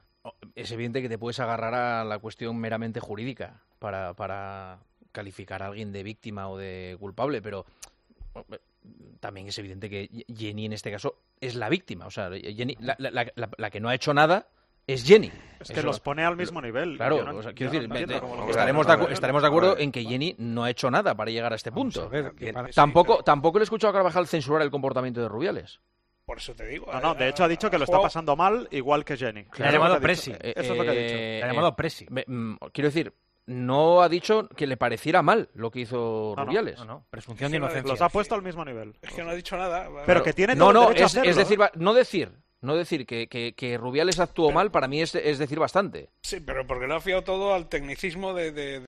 Los contertulios de El Partidazo de COPE comentaron las declaraciones de Dani Carvajal al respecto de Luis Rubiales y Jenni Hermoso, que han generado polémica.